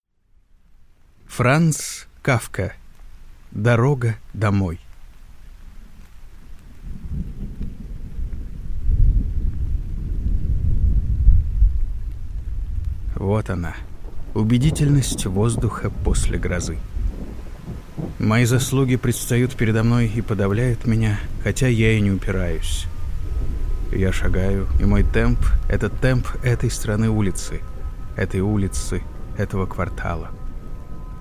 Аудиокнига Дорога домой | Библиотека аудиокниг